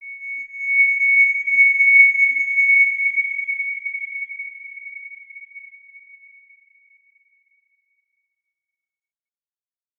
X_Windwistle-C#6-ff.wav